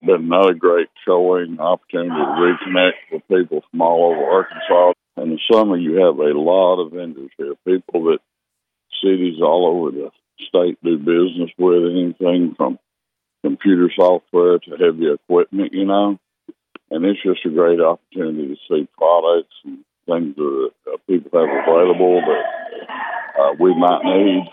KTLO News caught up with Mayor Hillary Adams Friday during the closing sessions of this years convention. The Mayor says that it’s been another productive convention for city staff.